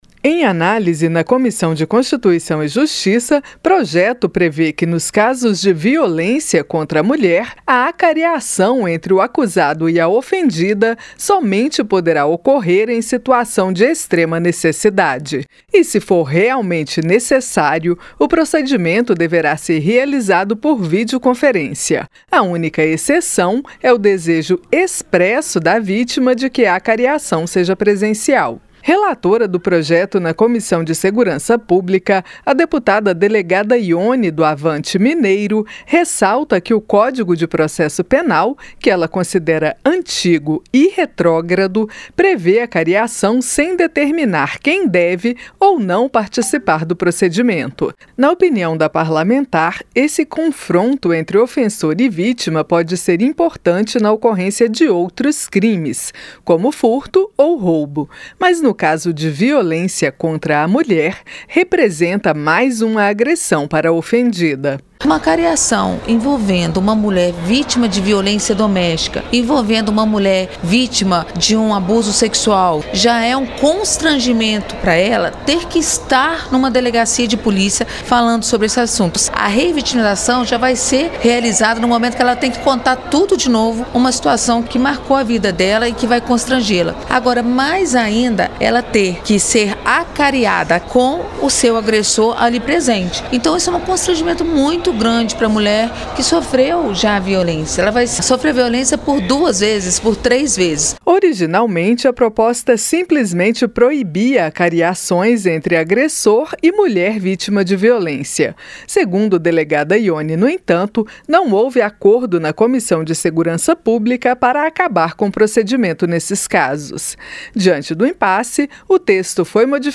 PROPOSTA QUE ESTÁ SENDO ANALISADA NA CÂMARA RESTRINGE ACAREAÇÃO ENTRE ACUSADO E VÍTIMA PARA ESCLARECER CRIMES DE VIOLÊNCIA CONTRA A MULHER. A REPÓRTER